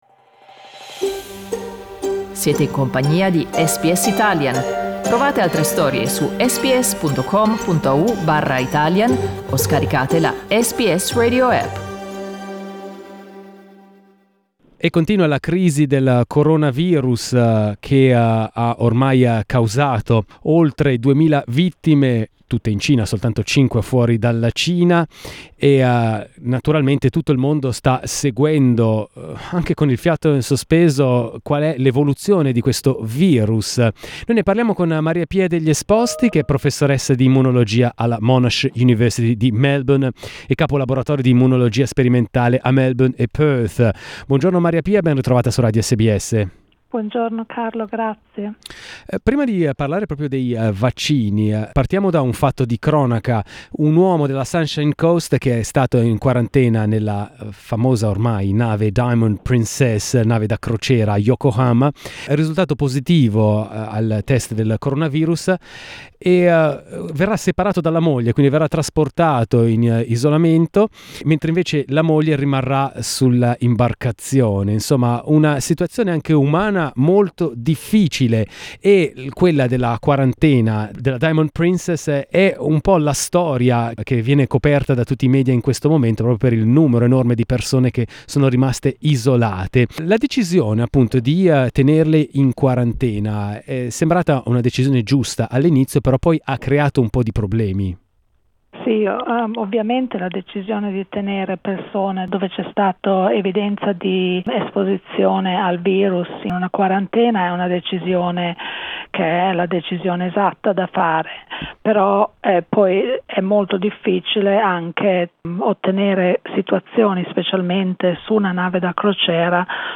Coronavirus, we speak to world-renowned researcher on the immune system